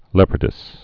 (lĕpər-dĭs)